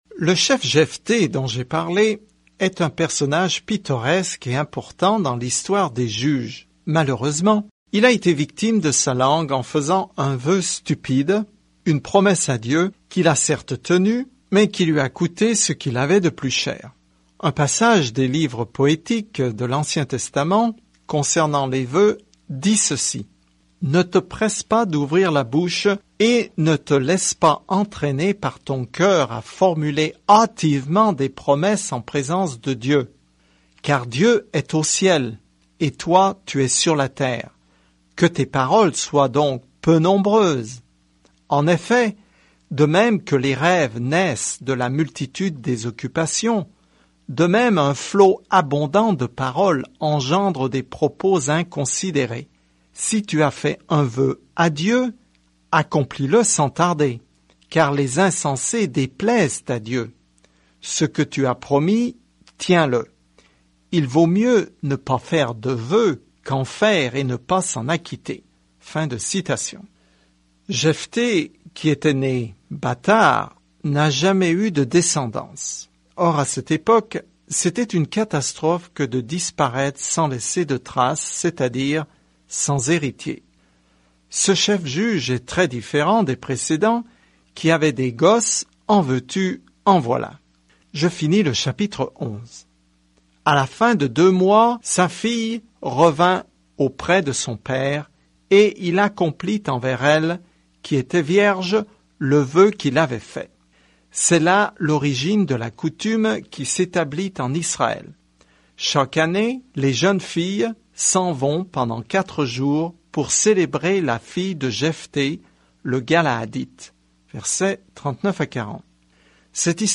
Écritures Juges 11:36-40 Juges 12 Juges 13 Juges 14:1-8 Jour 7 Commencer ce plan Jour 9 À propos de ce plan Les juges relatent la vie parfois tordue et bouleversée de personnes qui s’installent dans leur nouvelle vie en Israël. Parcourez quotidiennement les juges en écoutant l’étude audio et en lisant certains versets de la parole de Dieu.